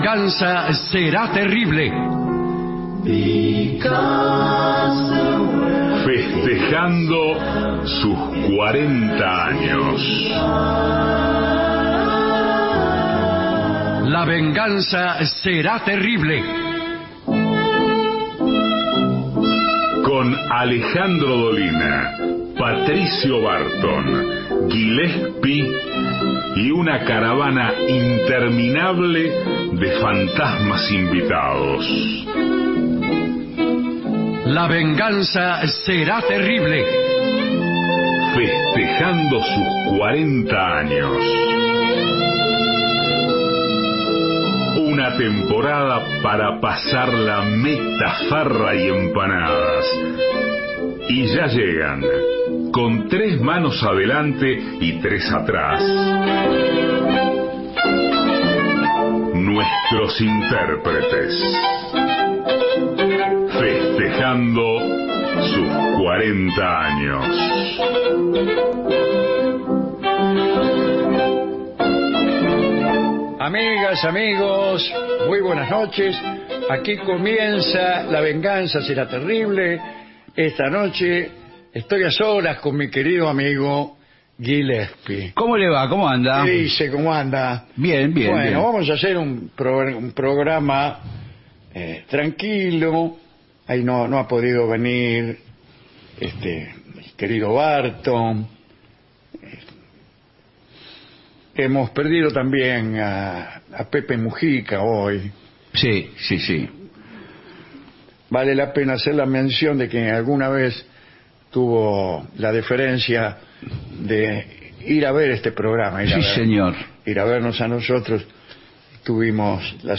Dolina canta los primeros versos en francés, luego entra Gillespi.